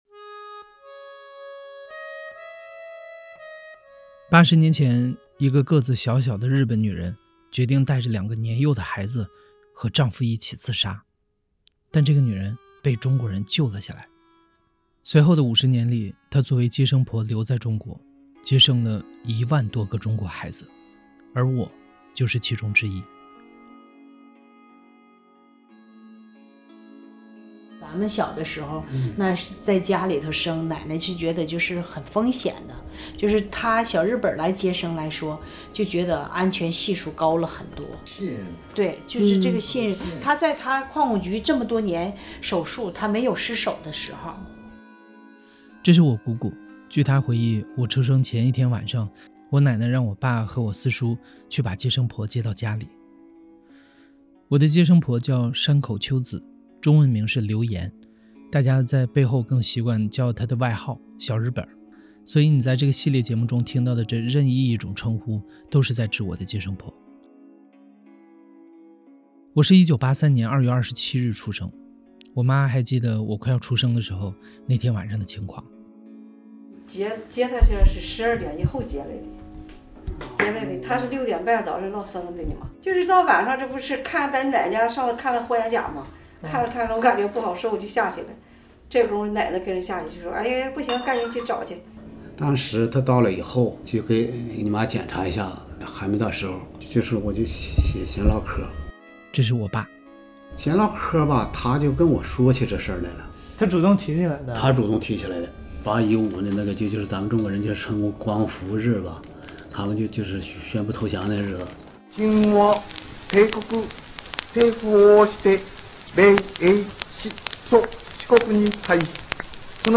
声音纪录片第一集 Staff
故事FM 是一档亲历者自述的声音节目。